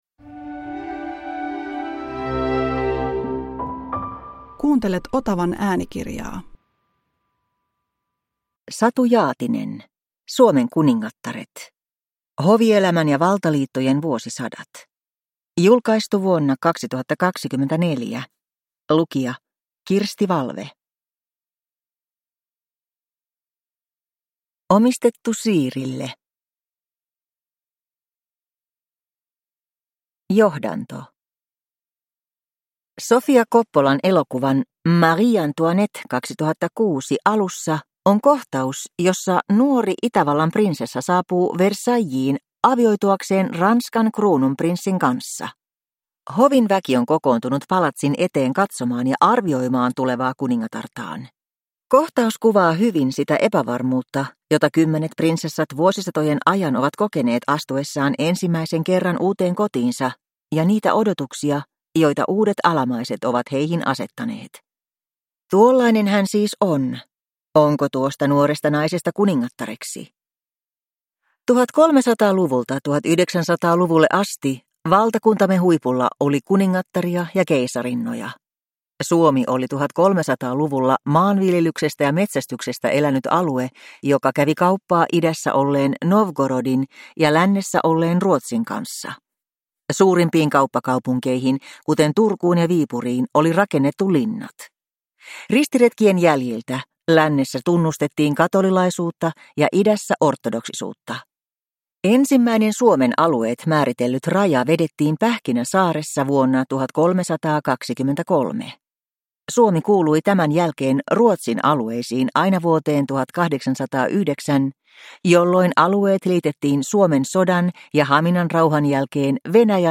Suomen kuningattaret – Ljudbok